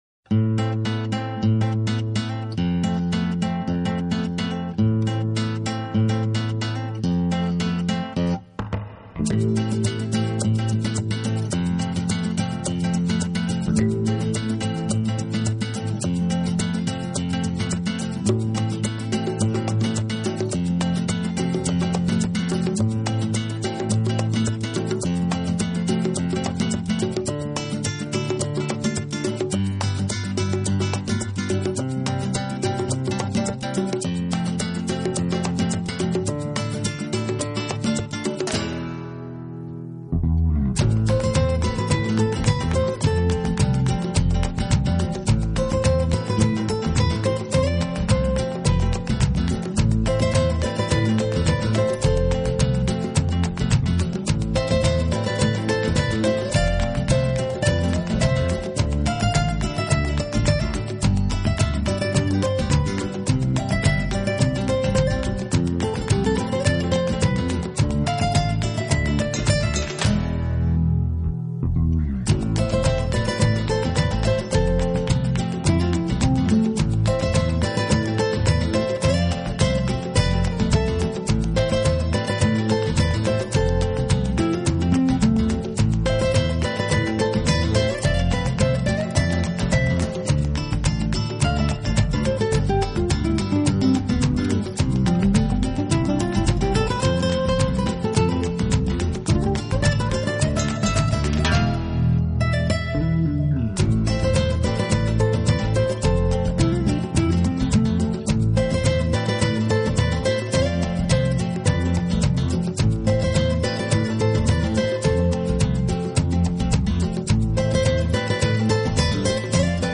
类型：New Age